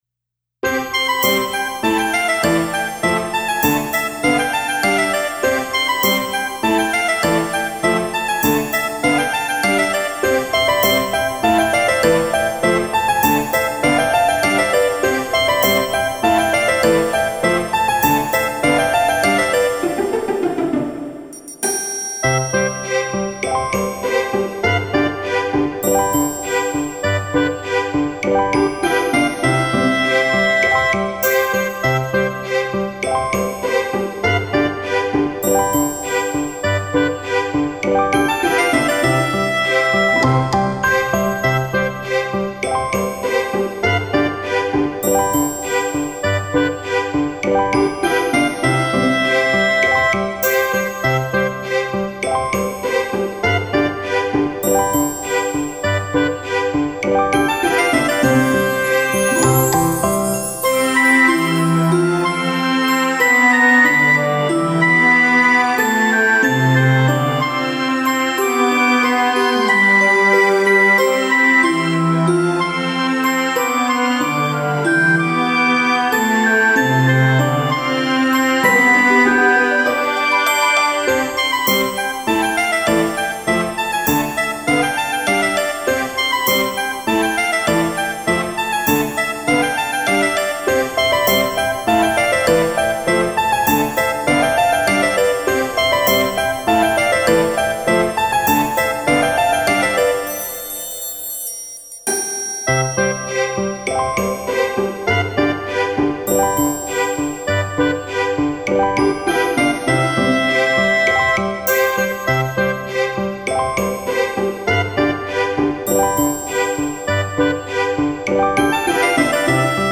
メルヘンチックなサウンドとメロディーが特徴の楽曲です。
コミカルで明るいシーンや料理やクッキングシーンなどに向いたBGMとなっています。